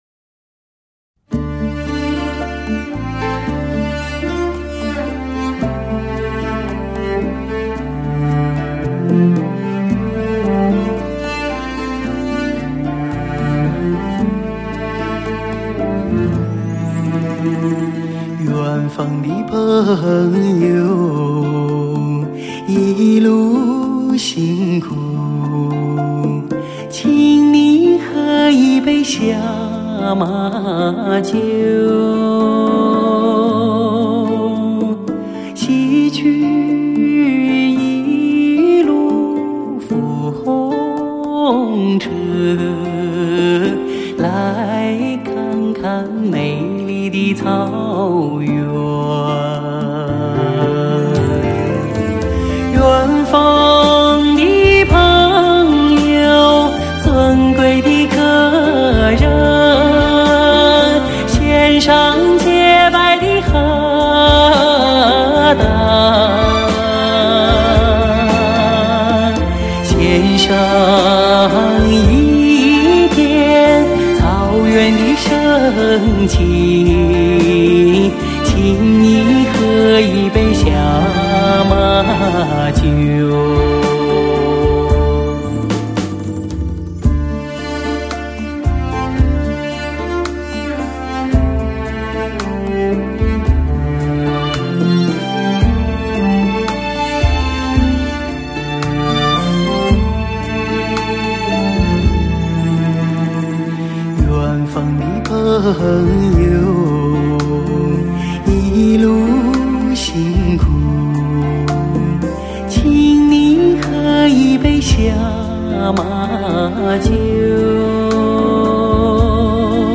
脍炙人口的草原民歌，音质完美的发烧经典。